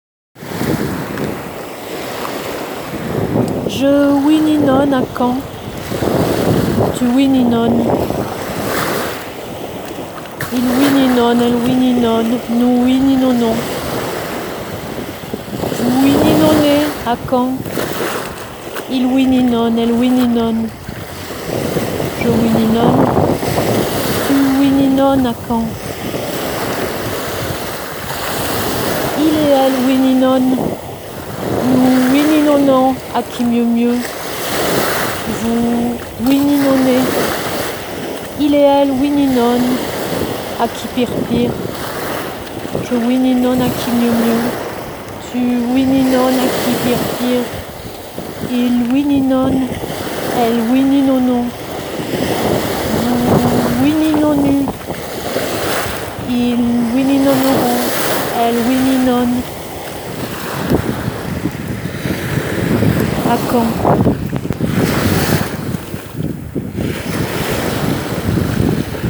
Le chant ouiouinonant de la sirène d'Hermanville sur Mer